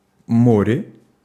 Ääntäminen
IPA: [ˈmo̞.rʲɪ]